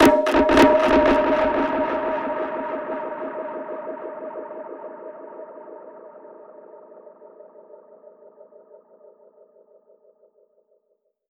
Index of /musicradar/dub-percussion-samples/85bpm
DPFX_PercHit_C_85-01.wav